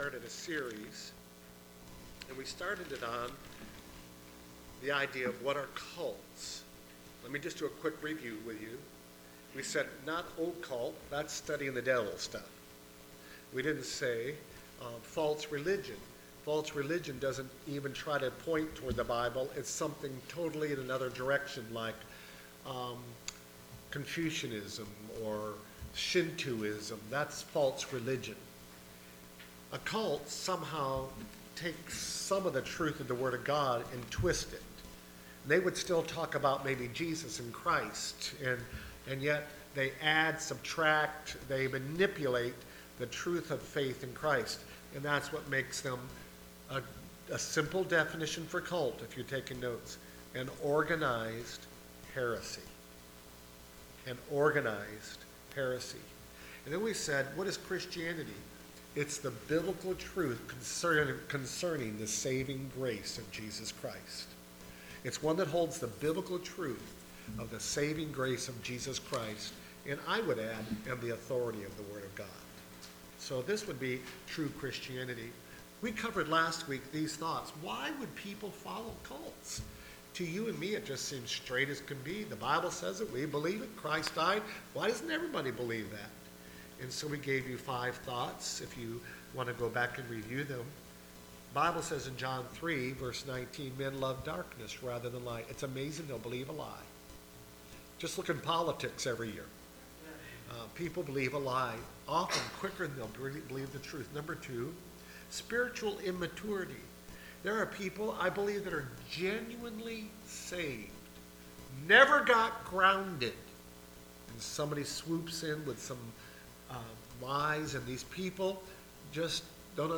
Service Type: Wednesday Prayer Service Preacher